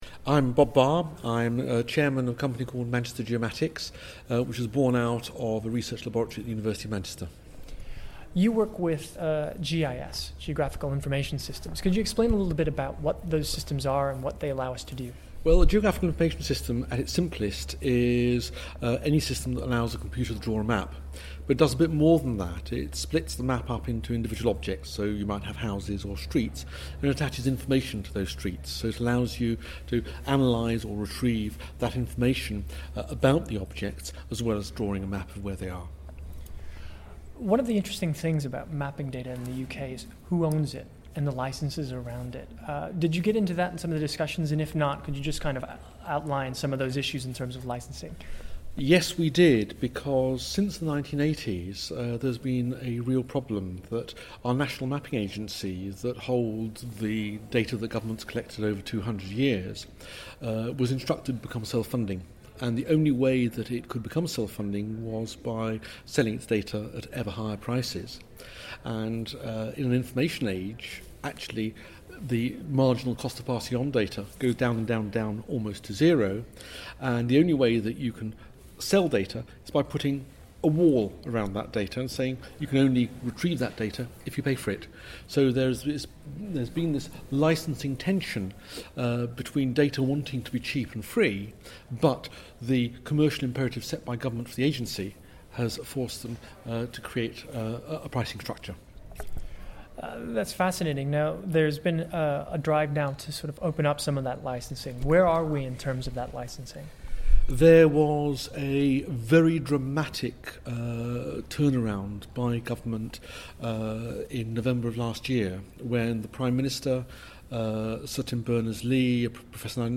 IBM Start Day 8 - Interview